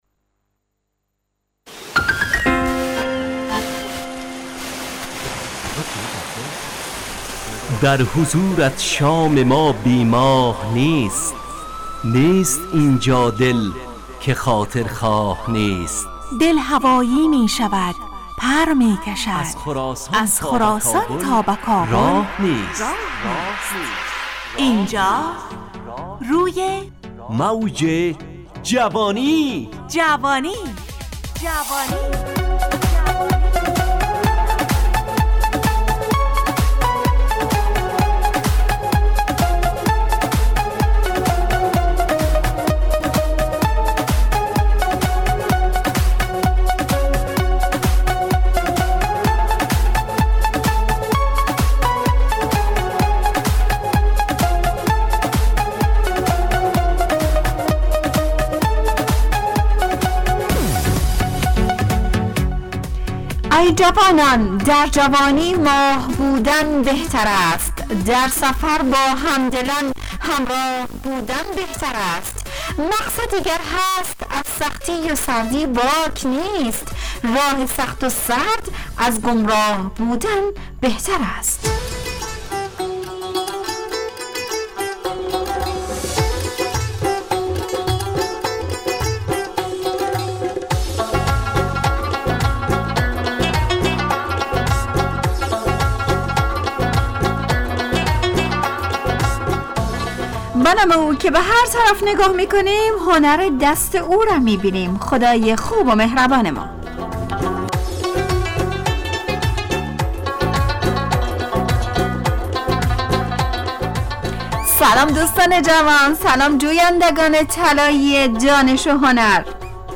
همراه با ترانه و موسیقی مدت برنامه 55 دقیقه . بحث محوری این هفته (هنر) تهیه کننده